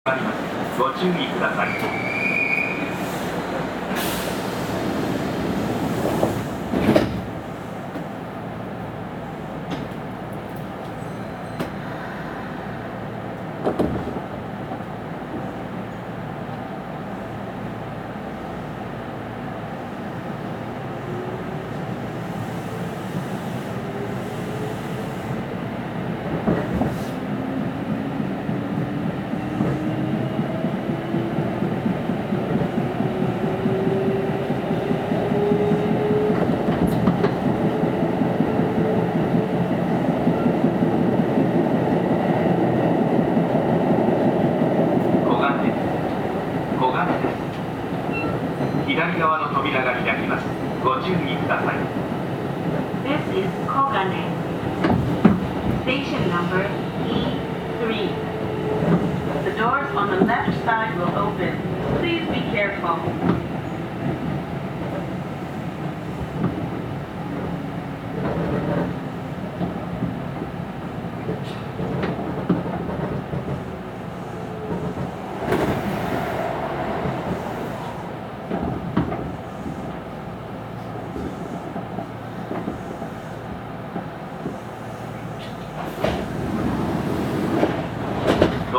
走行音
録音区間：烏森～黄金(お持ち帰り)